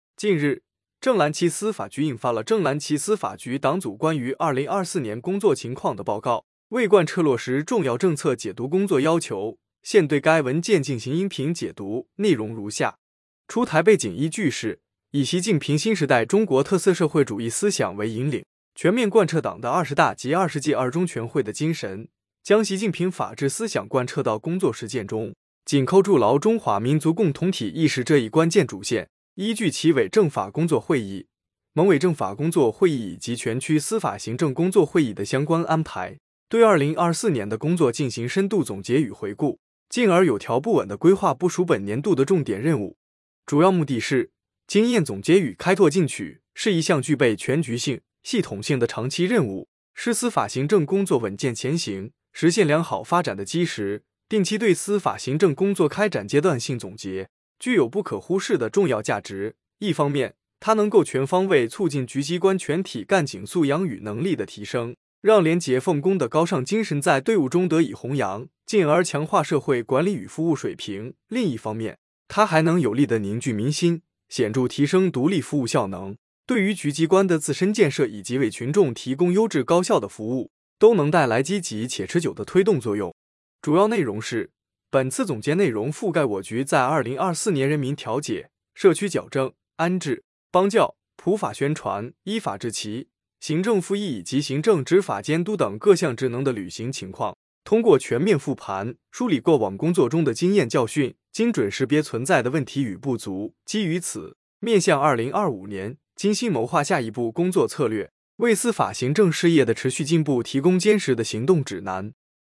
标题：【音频解读】《正蓝旗司法局党组关于2024年工作情况的报告》解读